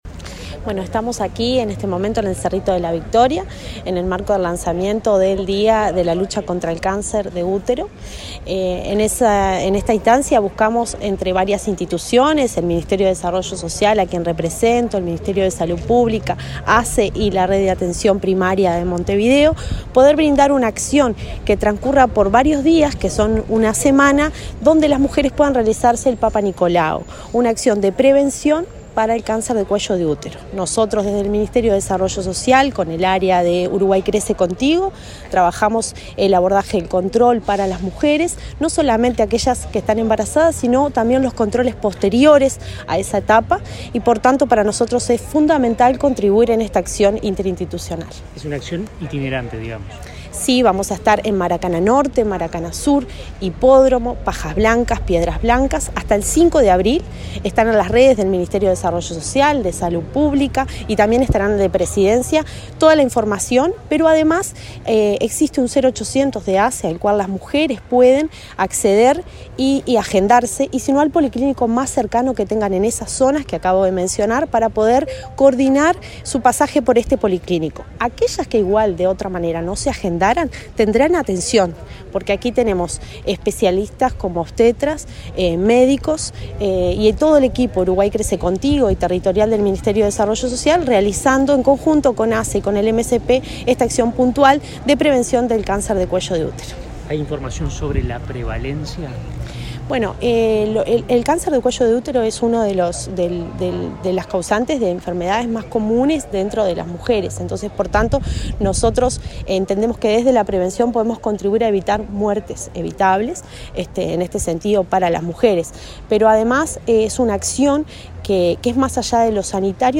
El Ministerio de Salud Pública (MSP), junto con su par de Desarrollo Social, el INJU ASSE y el Hospital de la Mujer del Pereira Rossell lanzaron jornadas para la realización del PAP a usuarias de ASSE de entre 21 y 69 años en un policlínico móvil dispuesto para detectar cáncer de cuello uterino. La directora nacional de Desarrollo Social del Mides, Cecilia Sena, explicó los alcances de estas jornadas.